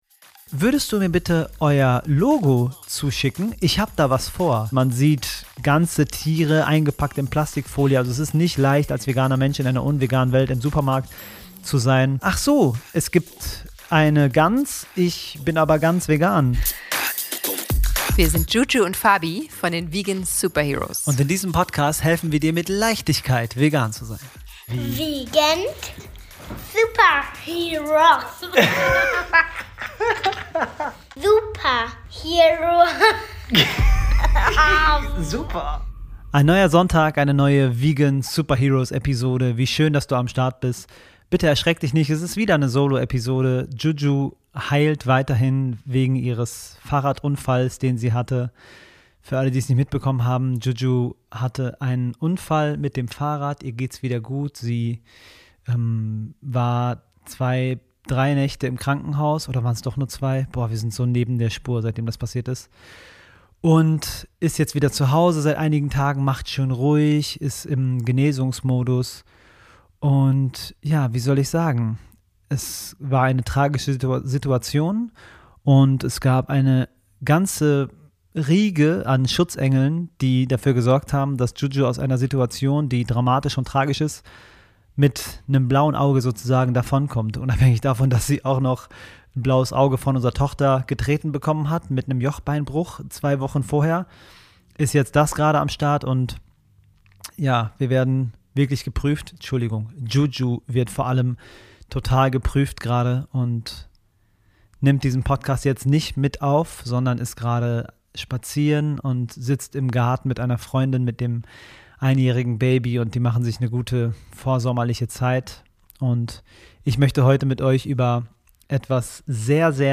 Spontanes Interview
Wir waren bei der DM-Zukunftswoche und haben uns spontan 5 wichtigen Fragen zur umsetzbaren Zukunftsfähigkeit der Ökologie gestellt.